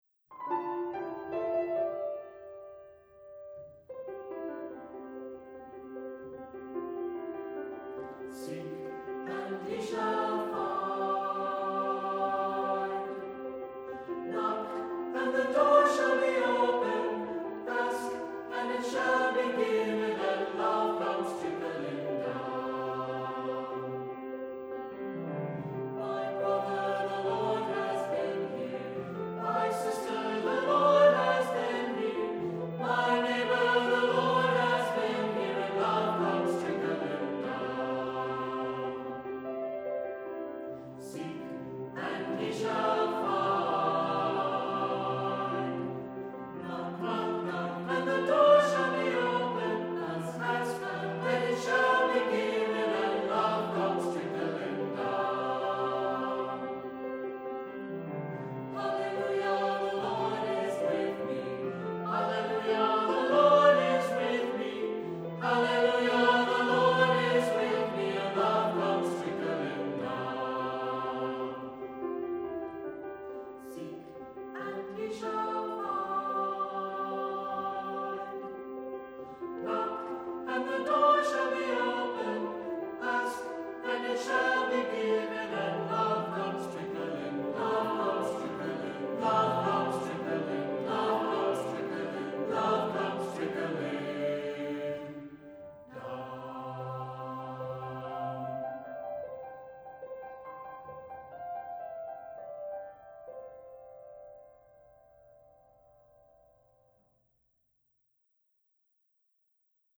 Voicing: Unison; Two-part equal